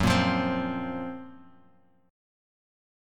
GbM7b5 chord